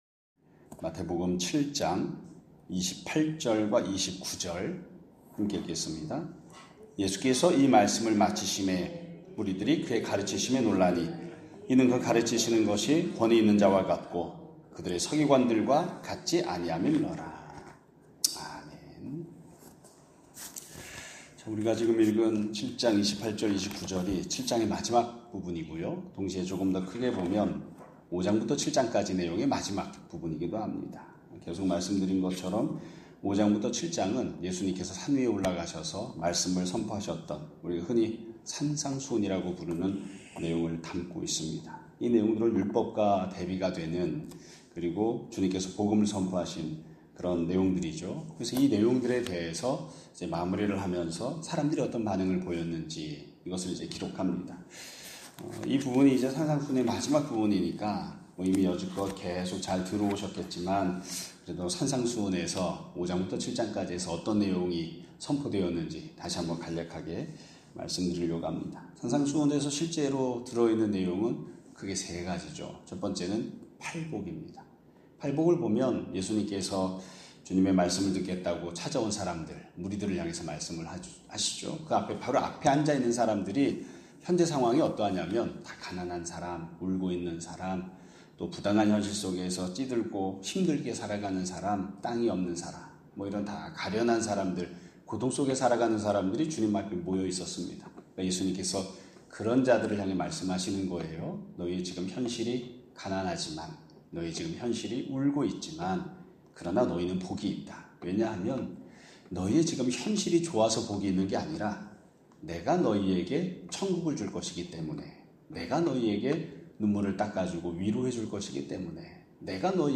2025년 7월 4일(금요 일) <아침예배> 설교입니다.